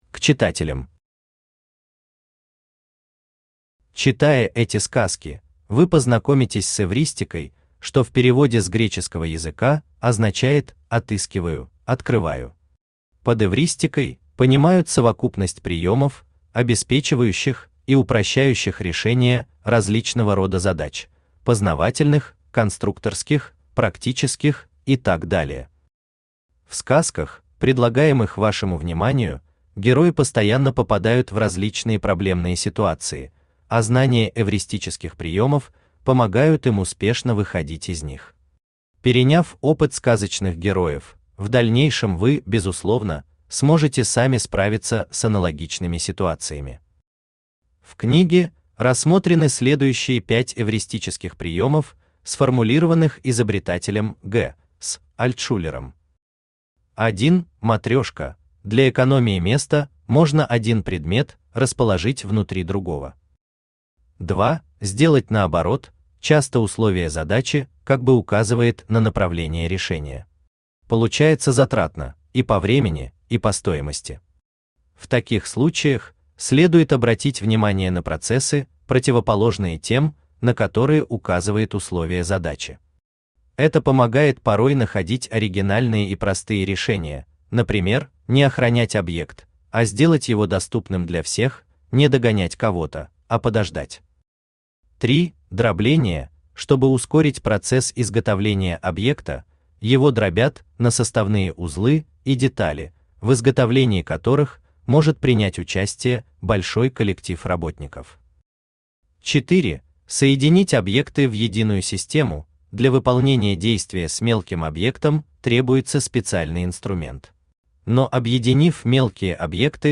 Аудиокнига Сказки, обучающие эвристическим приёмам | Библиотека аудиокниг
Aудиокнига Сказки, обучающие эвристическим приёмам Автор Вячеслав Вячеславович Тигров Читает аудиокнигу Авточтец ЛитРес.